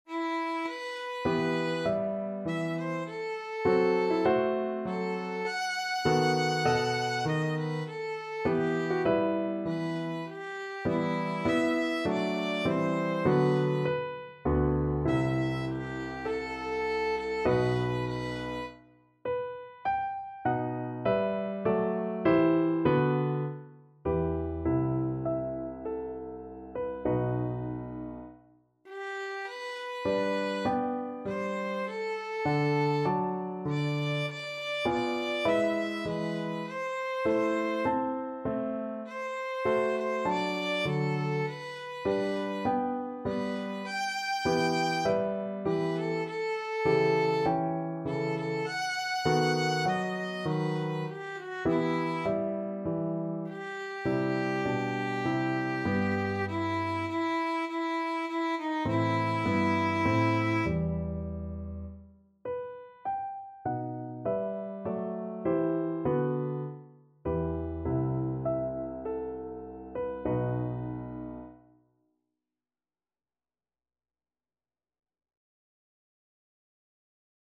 Classical Handel, George Frideric Behold and See from Messiah Violin version
Violin
4/4 (View more 4/4 Music)
Largo =c.100
E minor (Sounding Pitch) (View more E minor Music for Violin )
Classical (View more Classical Violin Music)
behold_and_see_VLN.mp3